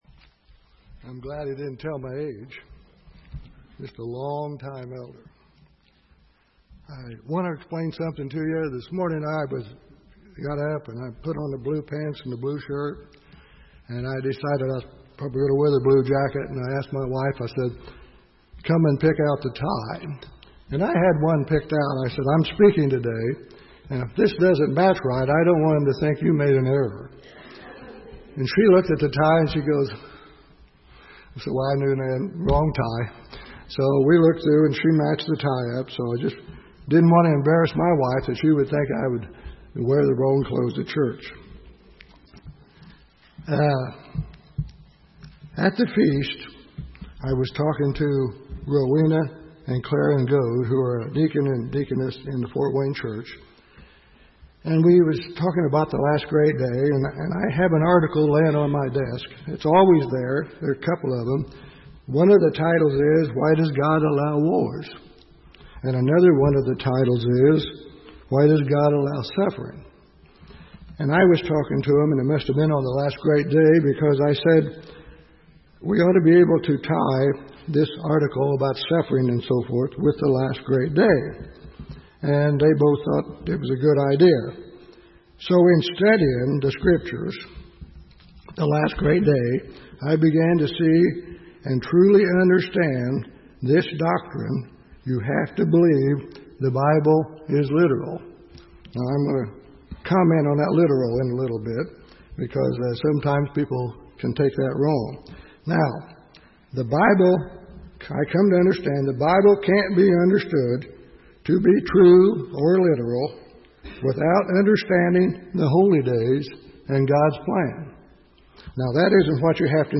Given in Indianapolis, IN
UCG Sermon Studying the bible?